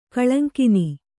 ♪ kaḷaŋkini